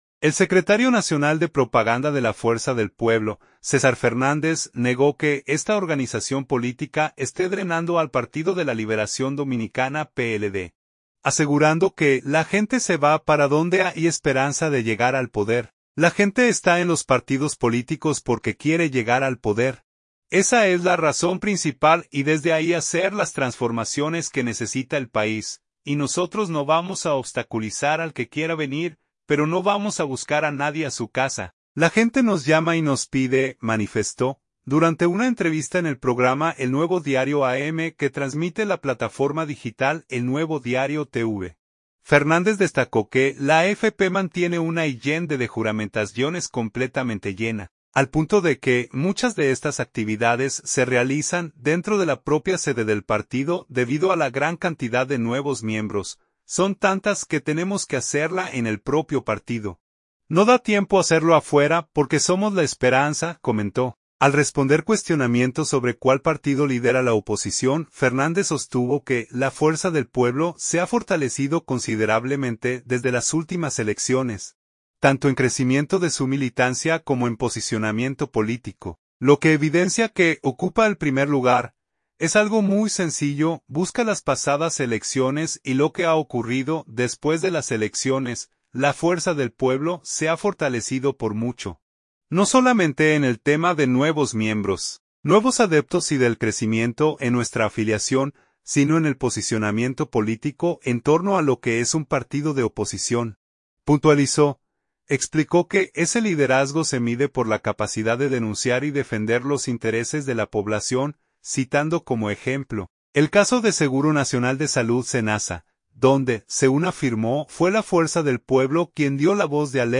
Durante una entrevista en el programa “El Nuevo Diario AM”, que transmite la plataforma digital El Nuevo Diario TV